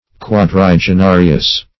Search Result for " quadrigenarious" : The Collaborative International Dictionary of English v.0.48: Quadrigenarious \Quad`ri*ge*na"ri*ous\, a. [L. quadrigeni, quadringeni, four hundred each.]